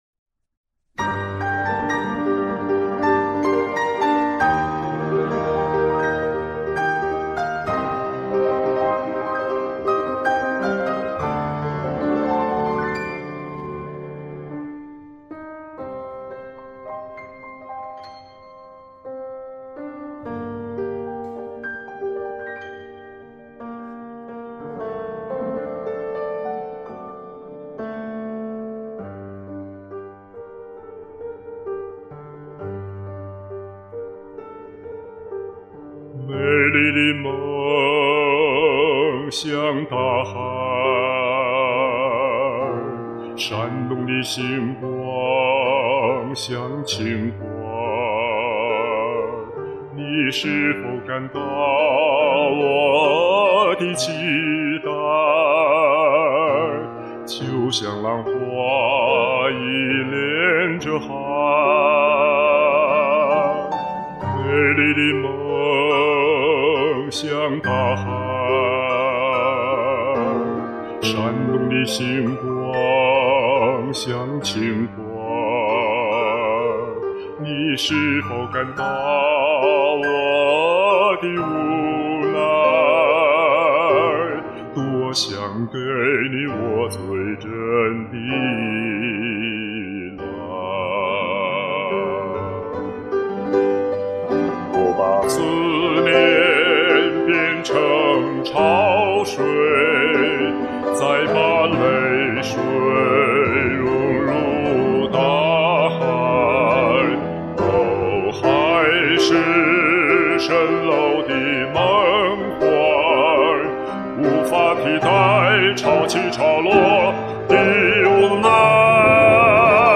情深似海，款款动人。。。
醇厚的男中音，赞！